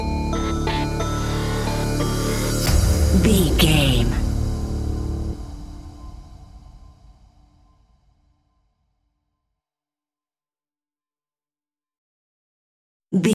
Growly Synth & Percussion Stinger.
Aeolian/Minor
ominous
dark
eerie
synthesiser
drums
electronic music